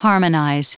Prononciation du mot harmonize en anglais (fichier audio)
Prononciation du mot : harmonize